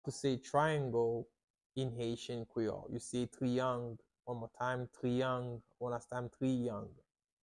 How to say "Triangle" in Haitian Creole - "Triyang" pronunciation by a native Haitian Tutor
“Triyang” Pronunciation in Haitian Creole by a native Haitian can be heard in the audio here or in the video below:
How-to-say-Triangle-in-Haitian-Creole-Triyang-pronunciation-by-a-native-Haitian-Tutor.mp3